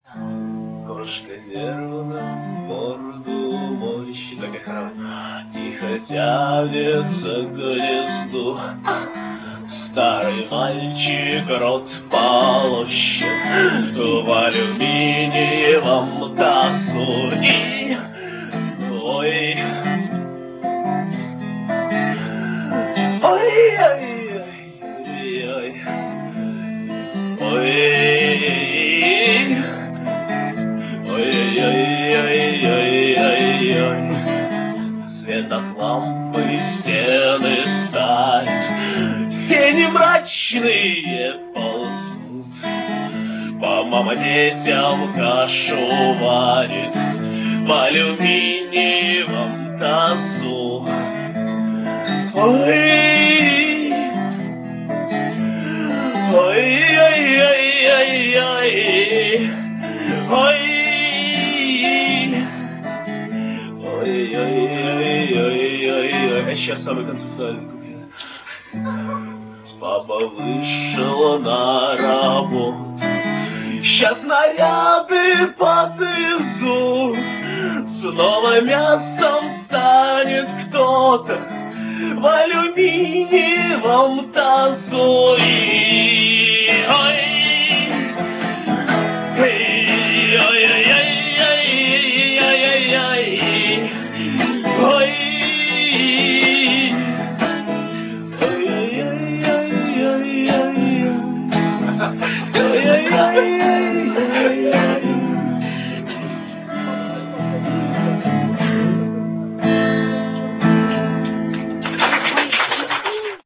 Квартирник 26 октября 1999.